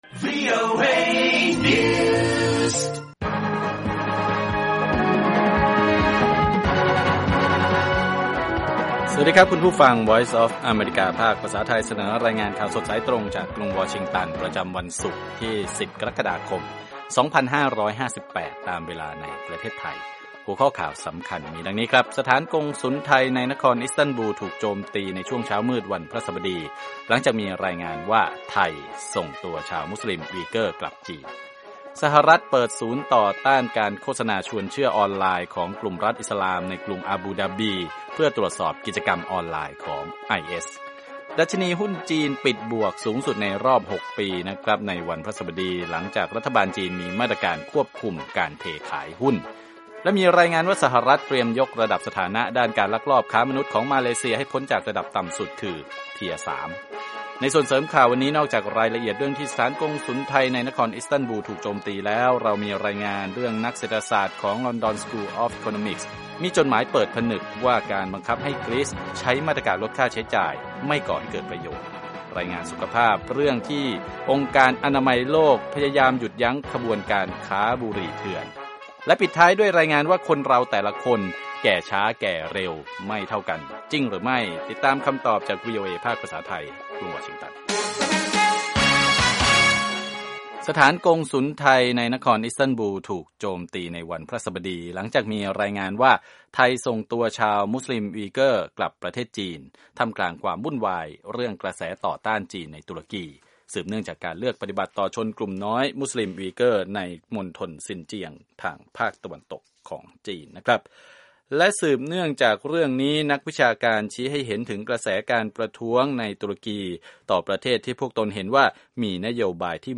ข่าวสดสายตรงจากวีโอเอ ภาคภาษาไทย 6:30 – 7:00 น. ศุกร์ ที่ 10 กรกฎาคม 2558